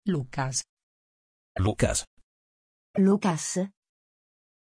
Aussprache von Loucas
Italienisch
pronunciation-loucas-it.mp3